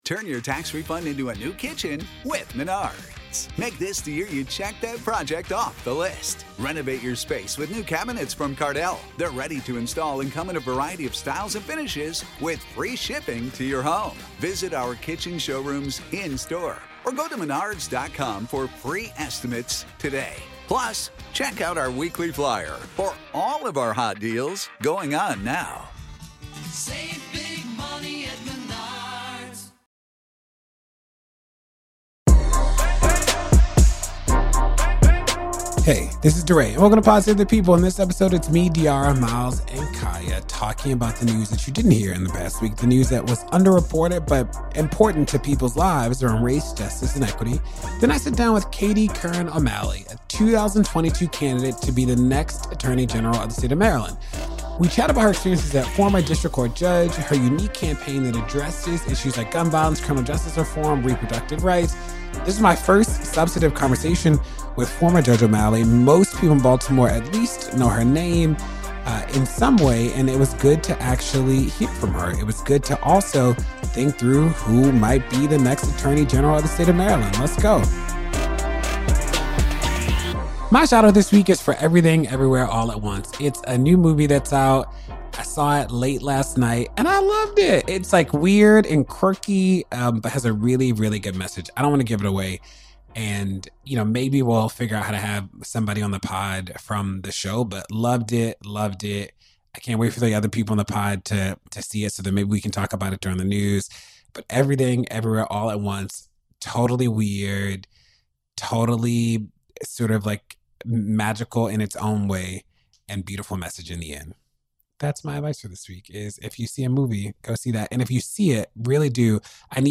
DeRay interviews Katie Curran O'Malley about her candidacy for Maryland Attorney General.